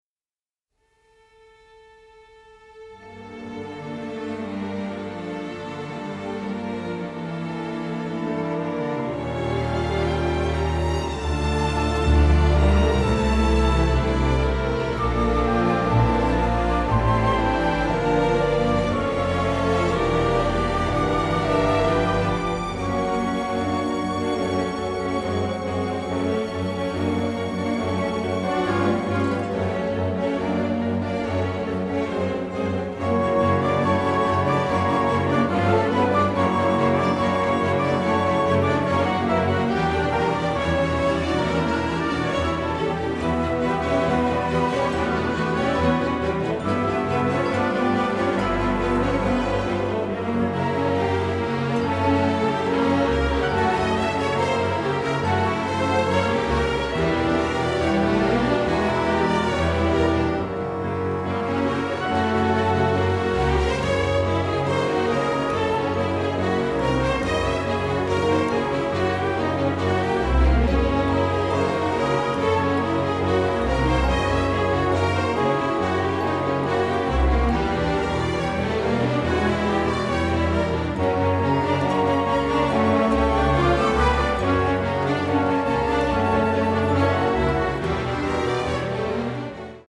delicate, subtle score